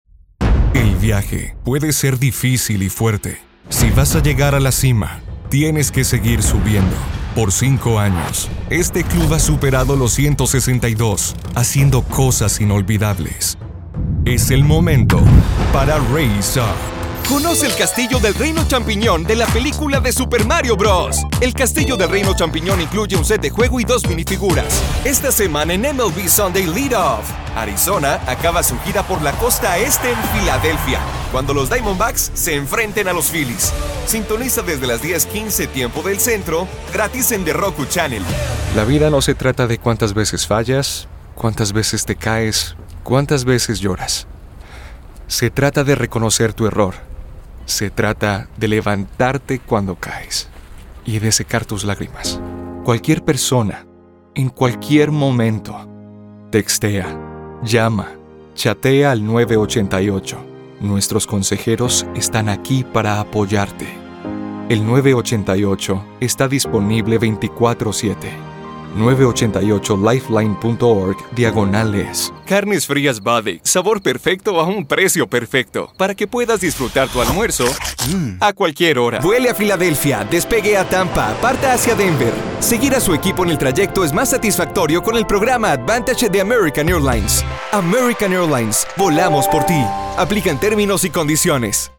Español (Latinoamericano)
Demo comercial
Adulto joven
Barítono
VersátilConversacionalEmocionalNaturalSuaveEnérgicoConfiadoConfiable